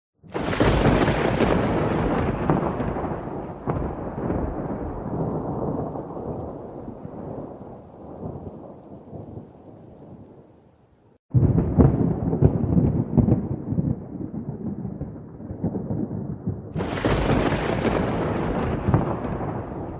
Free Ambient sound effect: Campfire Crackling.
Campfire Crackling
Campfire Crackling is a free ambient sound effect available for download in MP3 format.
403_campfire_crackling.mp3